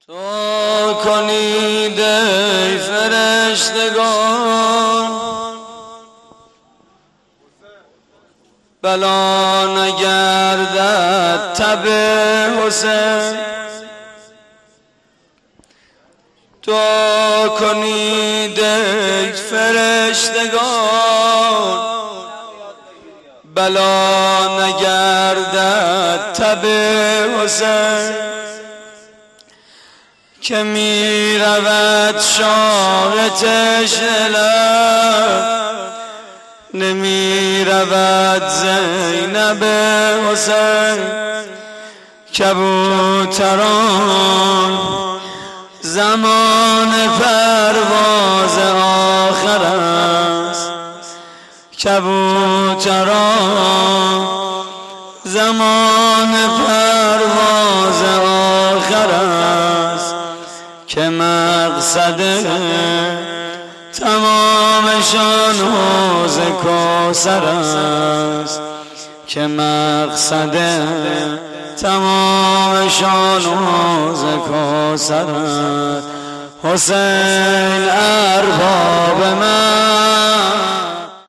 آوا: دعا کنید ای فرشتگان؛ پخش آنلاین |